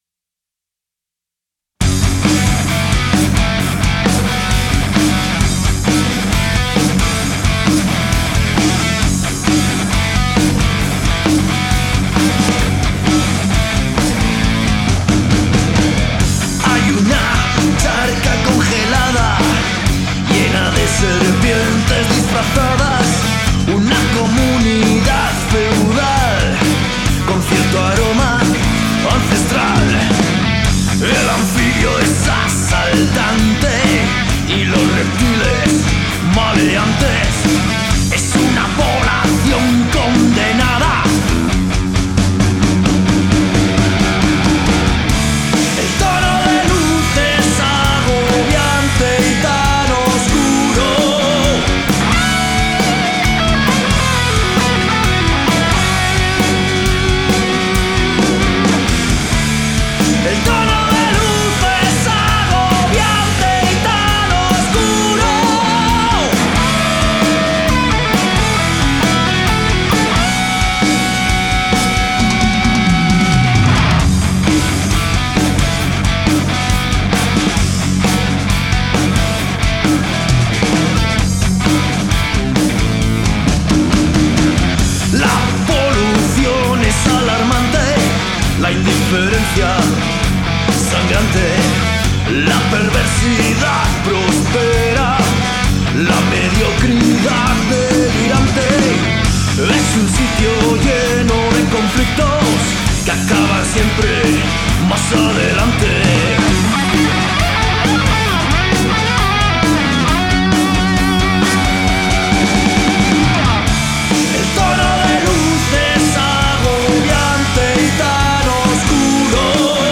REALIZACIÓN DE CONCIERTOS DE ROCK Y HEAVY